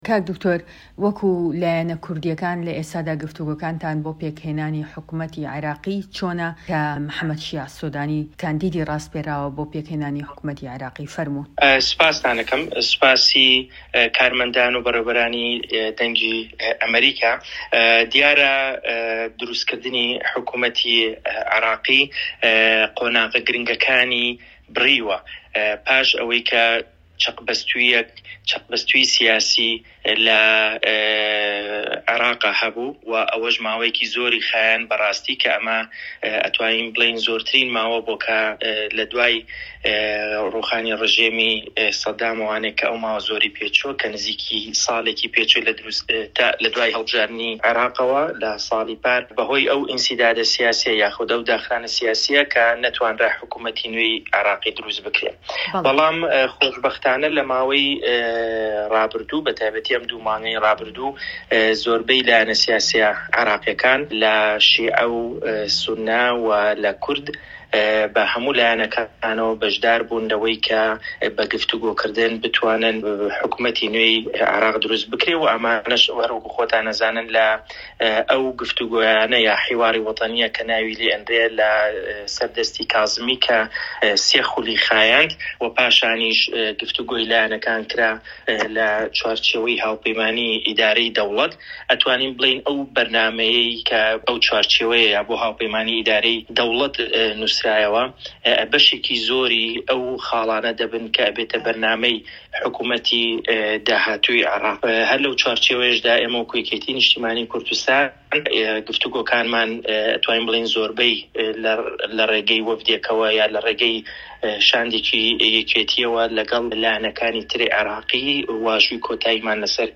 وتووێژەکەی